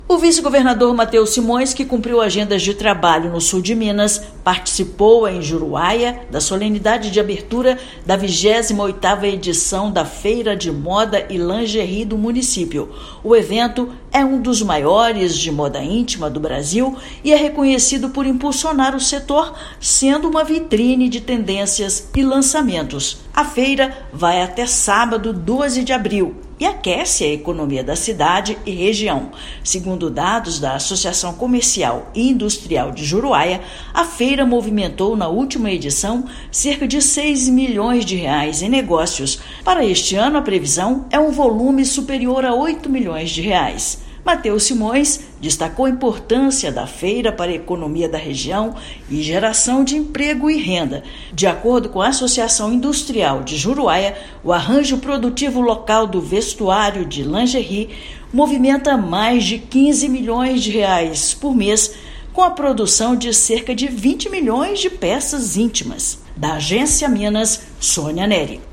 Mateus Simões, patrono do evento, destacou a importância do empreendedorismo feminino na cidade do Sudoeste de Minas. Ouça matéria de rádio.